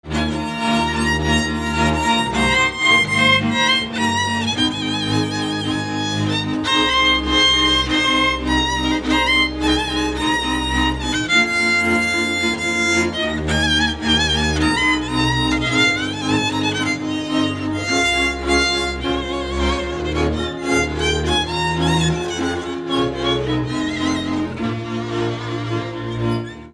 Dallampélda: Hangszeres felvétel
Erdély - Kis-Küküllő vm. - Teremiújfalu
hegedű
kontra (háromhúros)
bőgő
Műfaj: Lassú csárdás
Stílus: 1.1. Ereszkedő kvintváltó pentaton dallamok